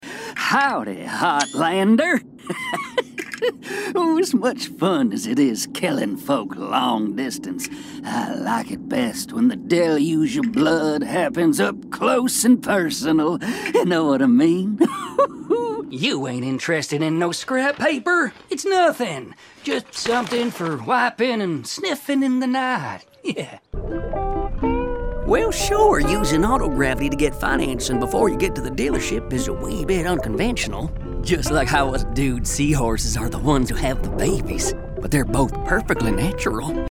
Demo
southern us